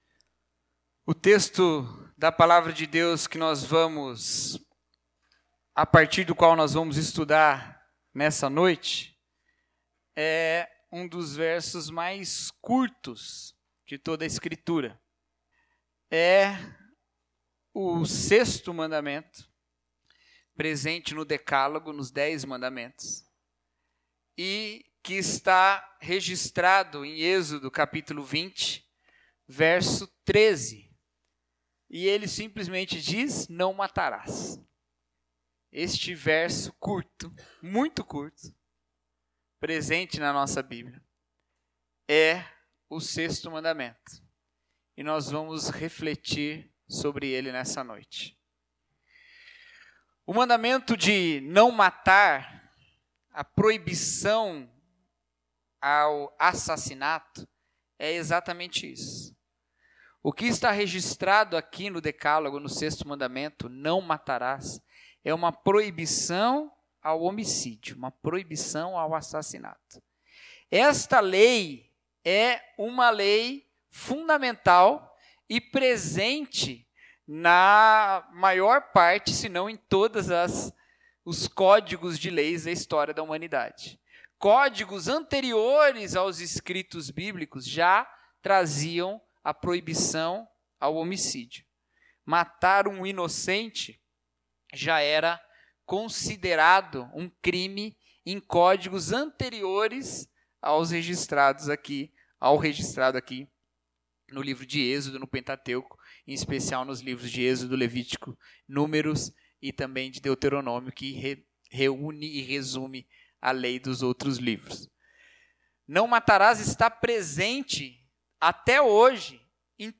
Mensagem: A Dignidade da Vida Humana